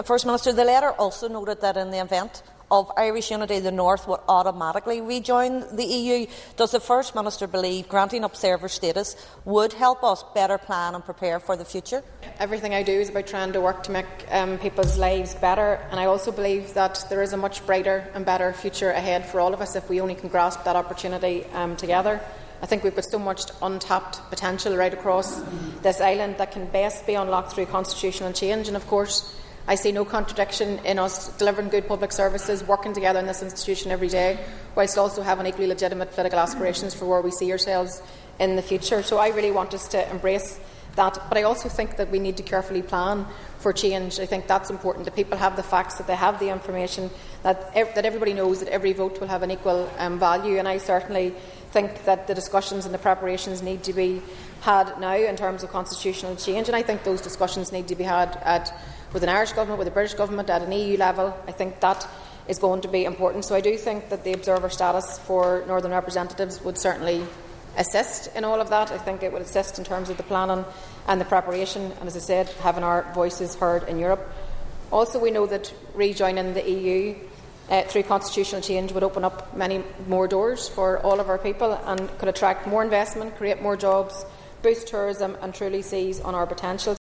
Foyle MLA Ciara Ferguson was speaking to First Minister Michelle O’Neill on the issue, saying the letter also noted that, in the case of a United Ireland, the North would rejoin the EU.
In response, Minister O’Neill says the discussions need to be had now, and the observer status would help: